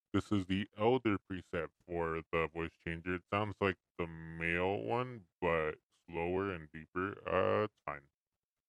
The SC3 comes with a voice changer feature.
Elder